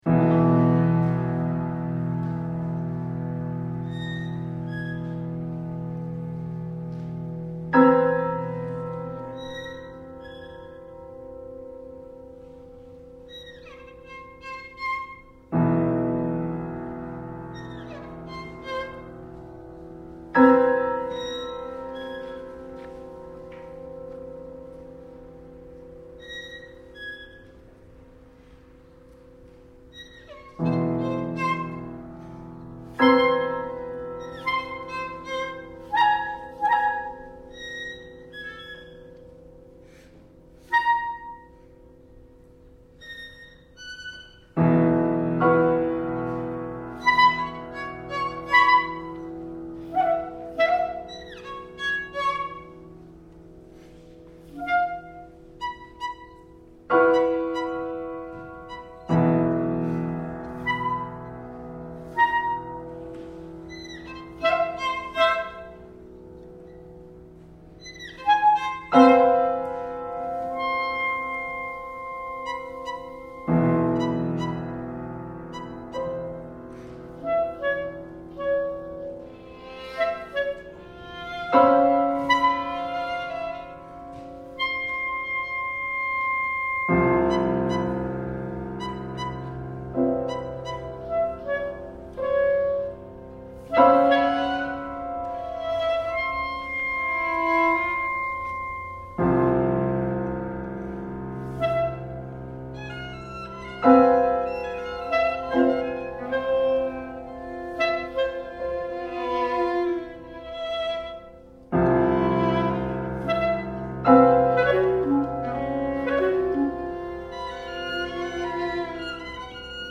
a composition for chamber ensemble
(1998) for clarinet, violin, cello, and piano. 10 minutes.